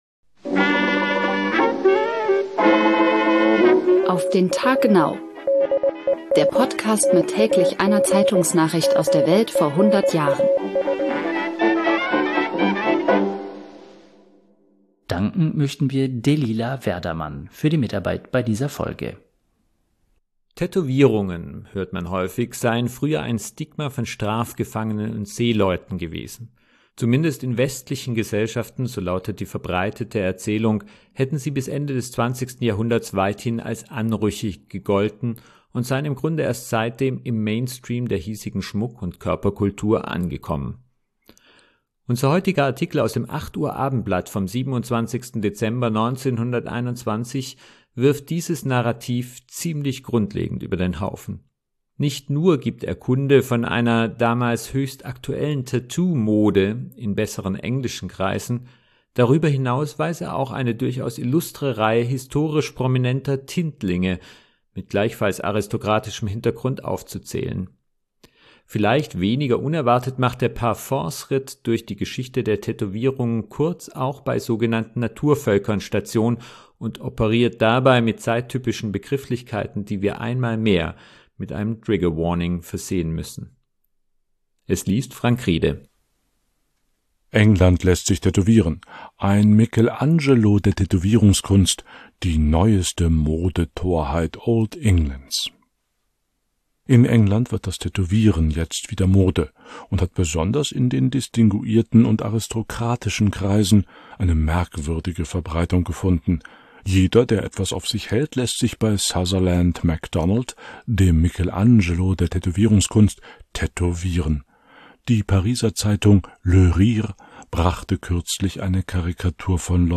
Der Podcast mit täglich einer Zeitungsnachricht aus der Welt vor hundert Jahren